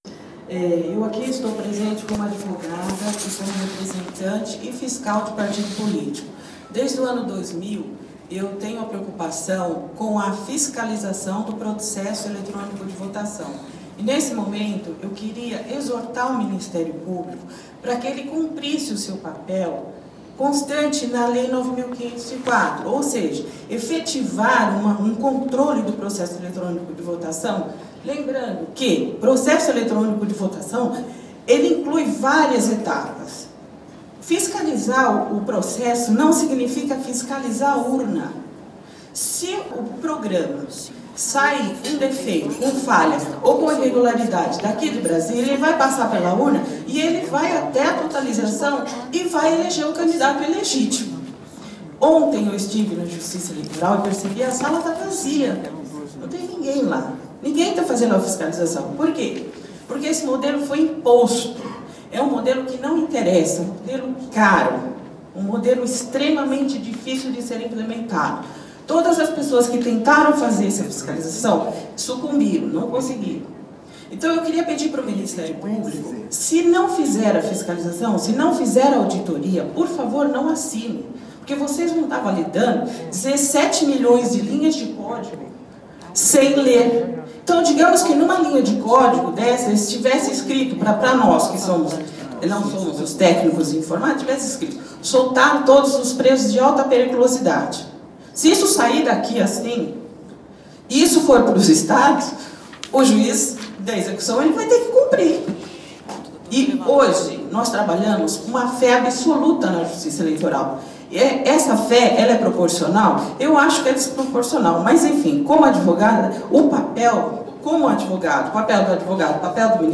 09/04/2014 - Audiência Pública no Ministrio Público com o Procurador Geral Eleitoral
audienciaMPmar2014.mp3